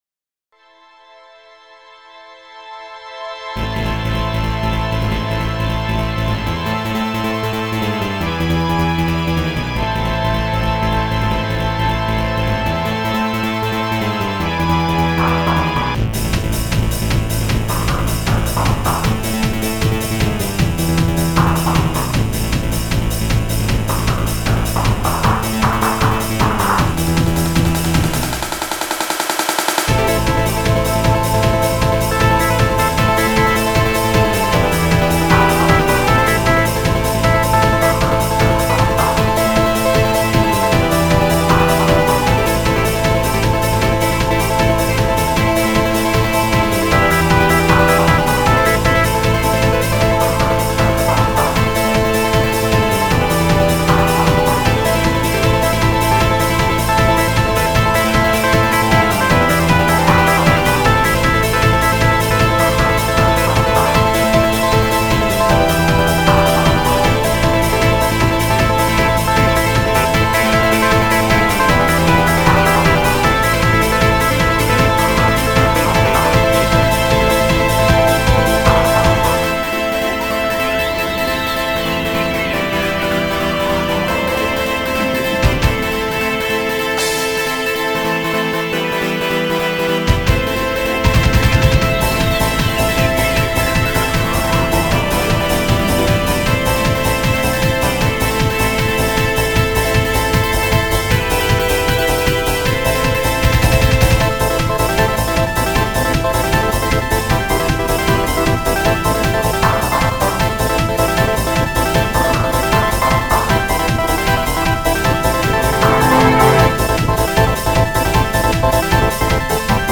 復帰後最初の曲はソフトFMシンセをいじり倒した結果生まれた作品です。
ソフトFMシンセで大半の音を作成しています。